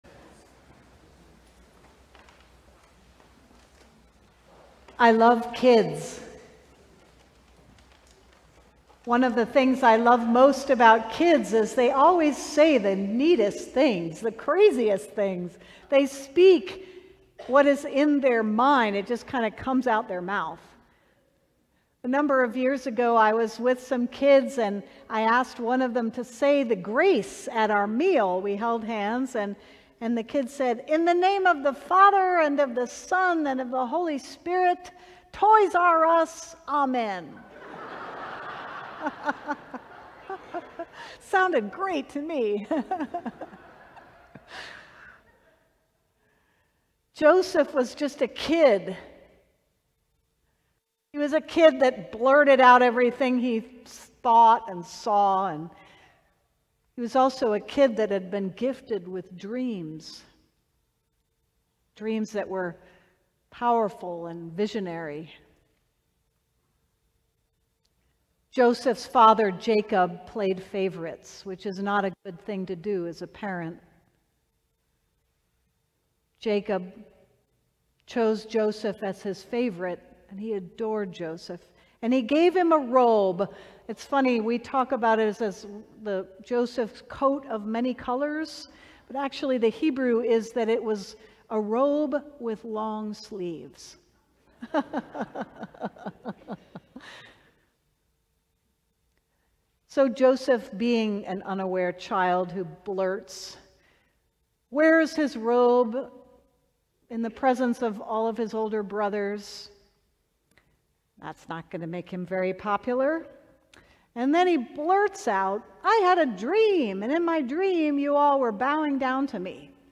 Sermon: Radical Jesus